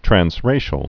(trăns-rāshəl, trănz-)